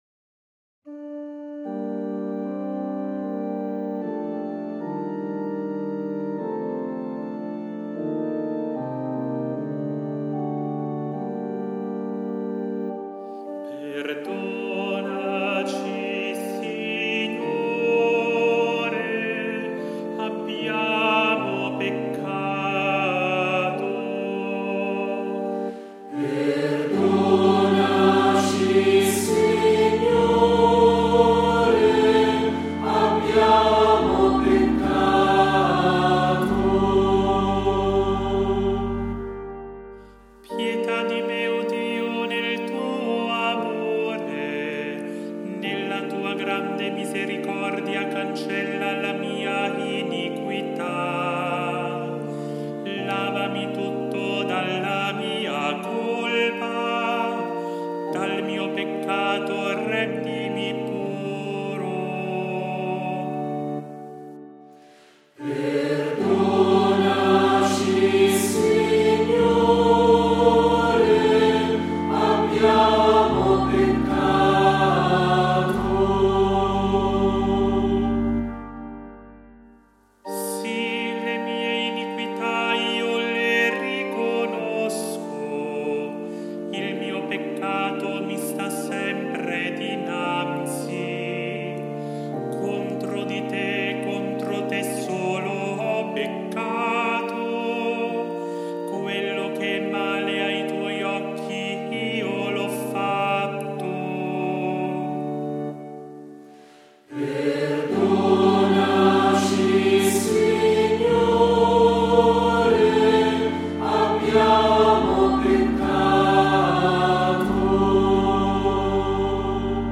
IL SALMO RESPONSORIALE